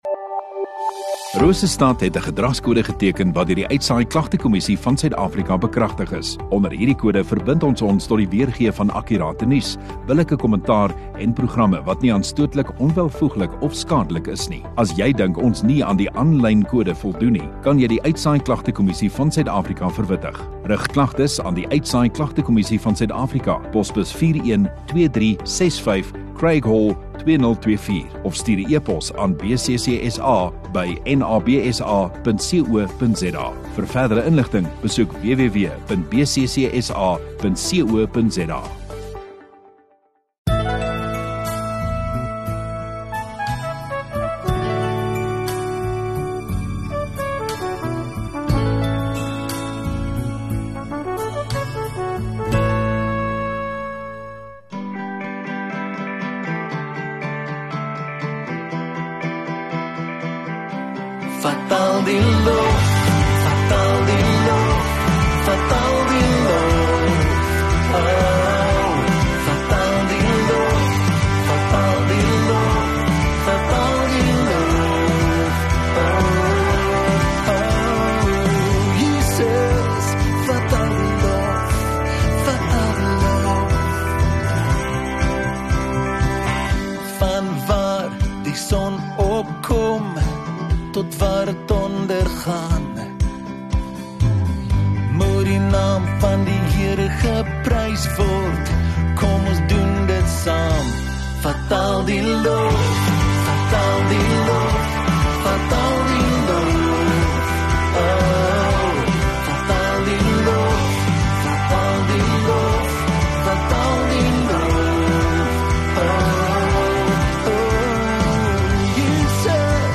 16 Feb Sondagaand Erediens